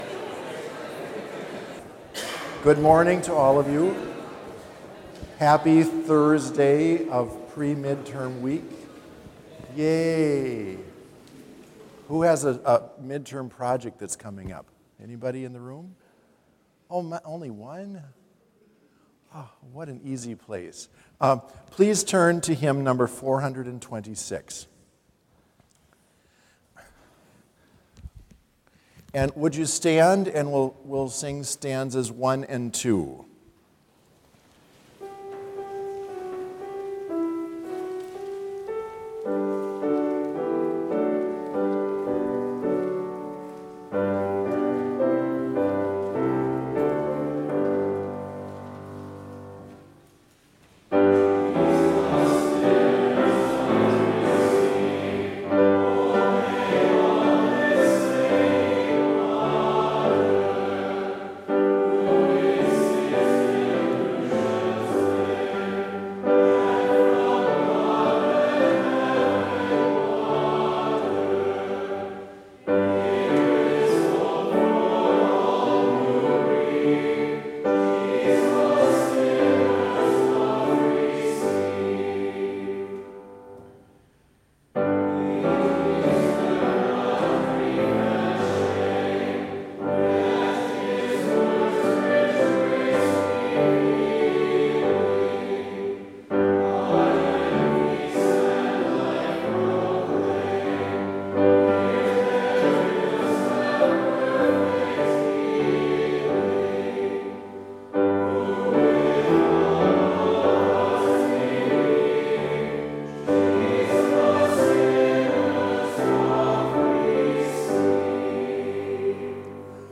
Complete service audio for Chapel - October 7, 2021